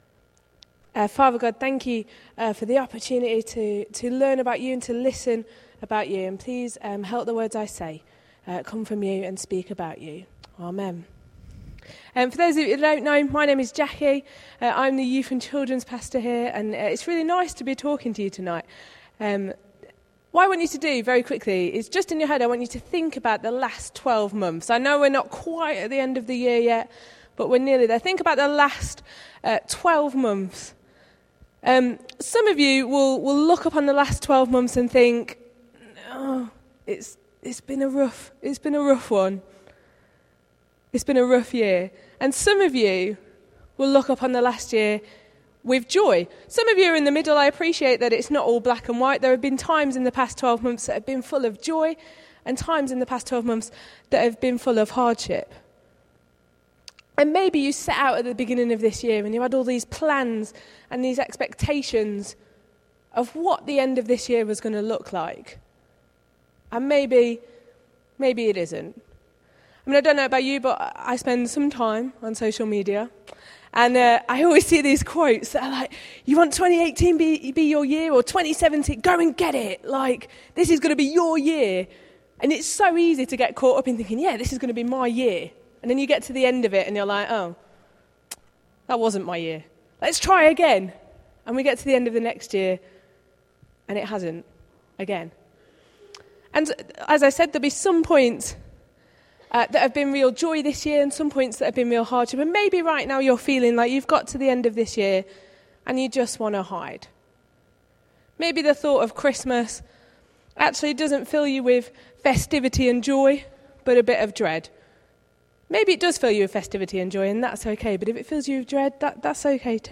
Carol Service message – St James New Barnet